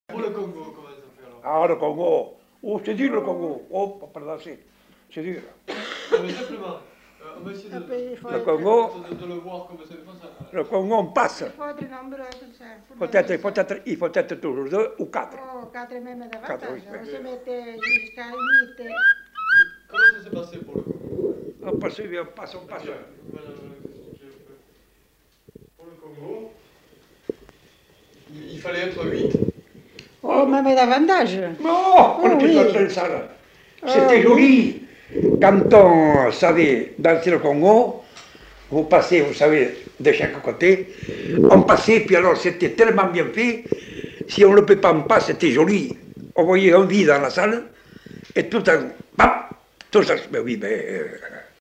Aire culturelle : Bazadais
Genre : témoignage thématique